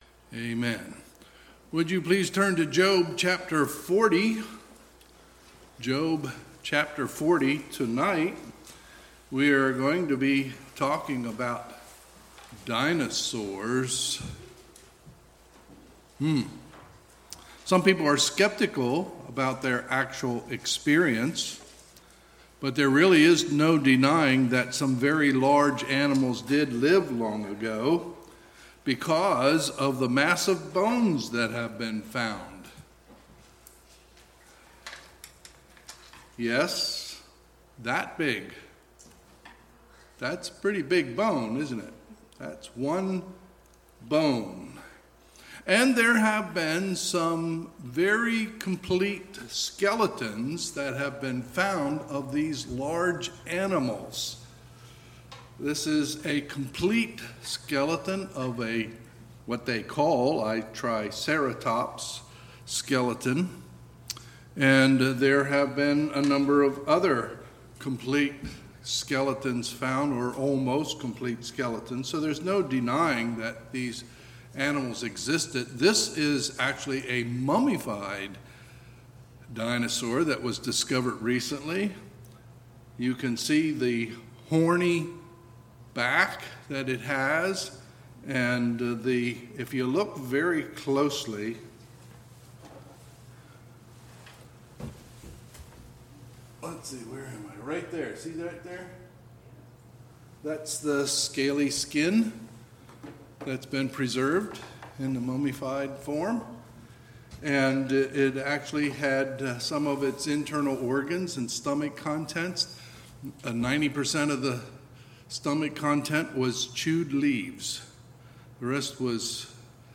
Sunday, August 15, 2021 – Sunday PM
Sermons